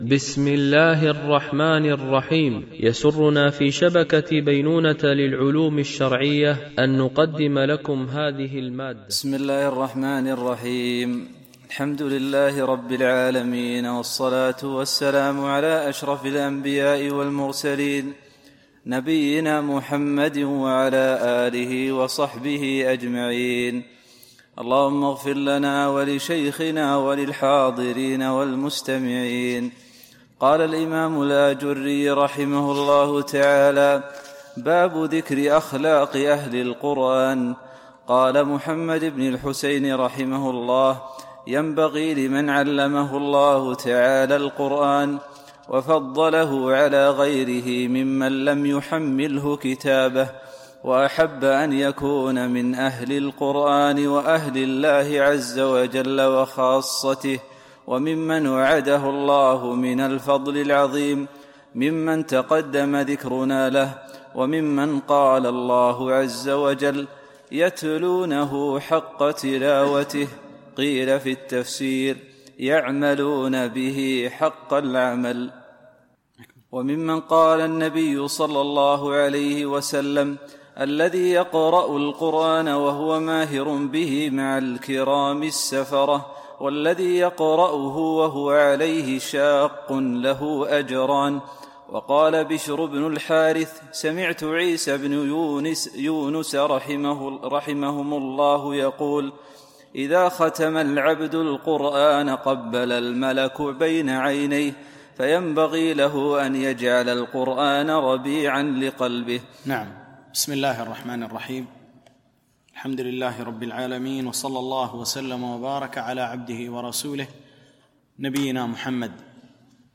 شرح آداب حملة القرآن ـ الدرس 4